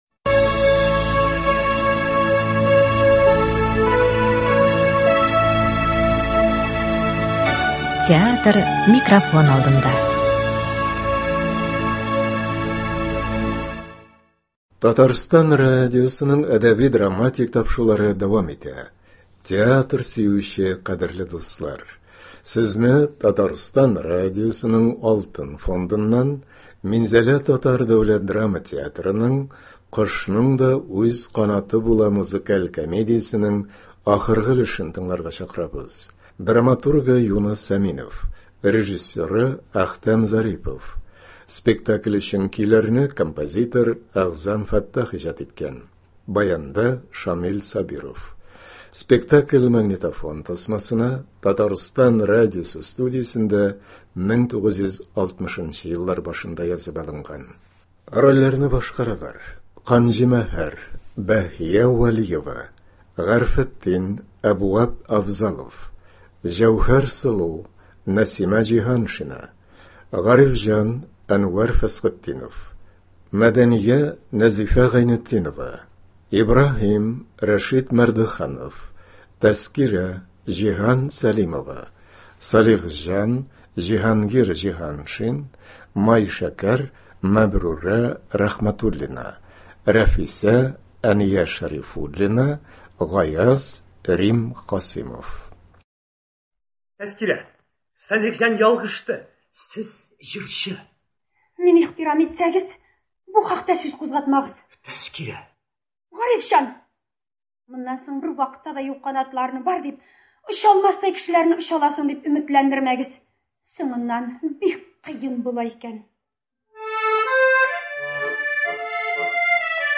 Минзәлә ТДДТ спектакле.